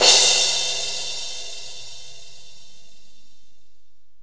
CYM18   01-R.wav